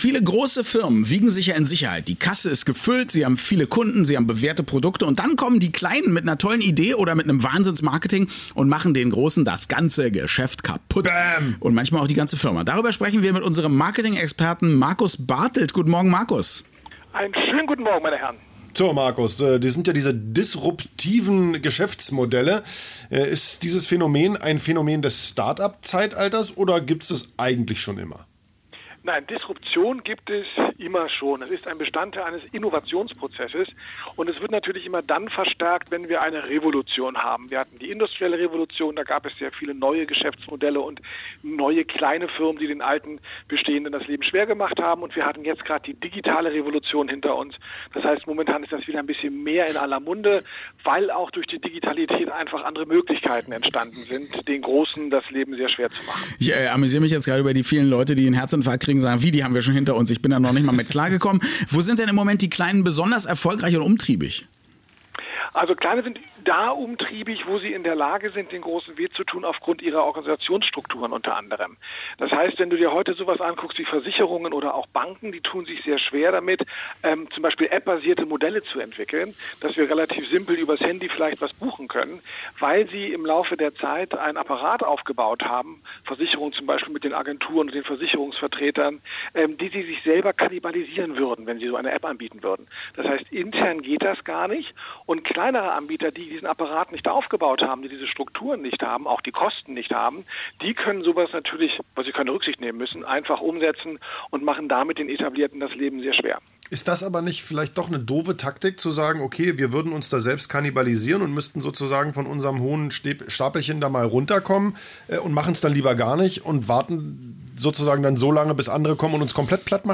Grund genug, ein wenig zu dem Thema mit den Herren von „Zwei auf eins“ zu plauschen. Leider konnte ich heute wieder nicht ins Studio fahren, denn ich musste meine Geburtstagsfeier vorbereiten: